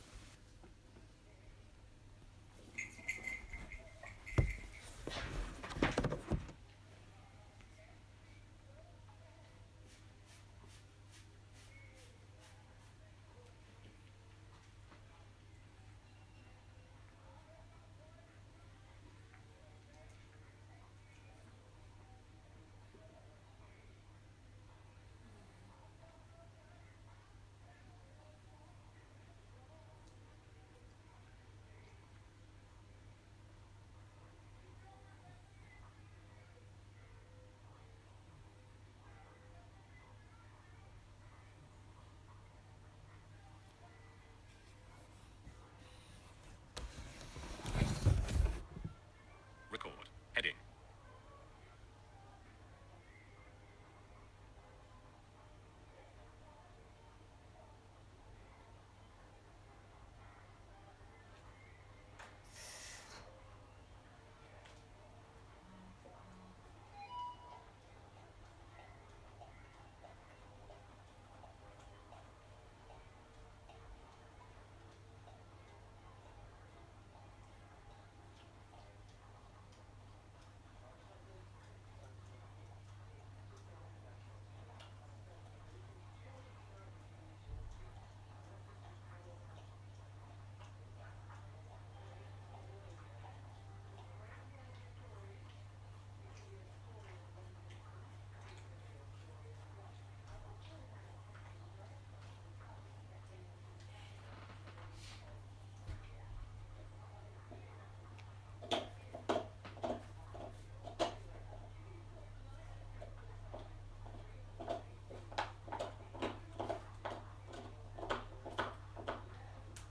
Quiet evening soundscape, Monday 27 March 2017